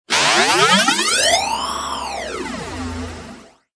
Descarga de Sonidos mp3 Gratis: videojuegos 4.